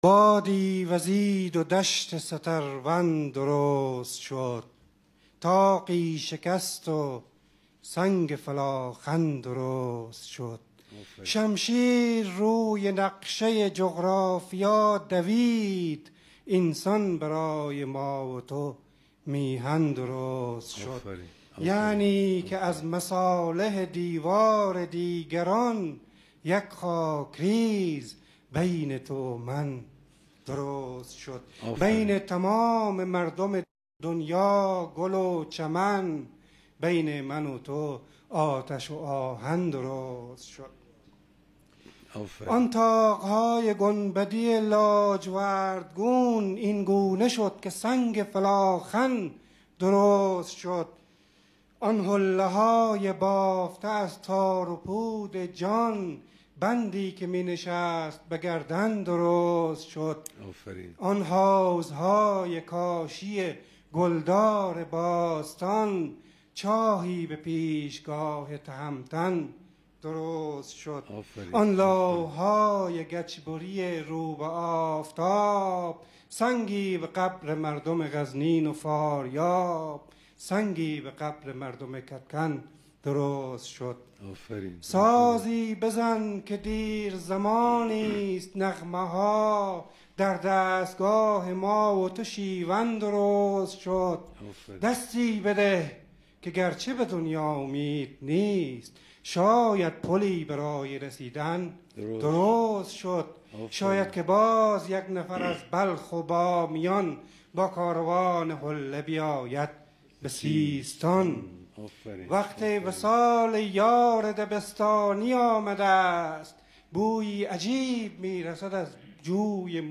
دریافت شمشیر و جغرافیا با صدای محمد کاظم کاظمیدانلود شمشیر و جغرافیا با صدای محمد کاظم کاظمی